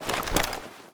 ak74_draw.ogg